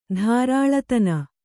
♪ dhārāḷatana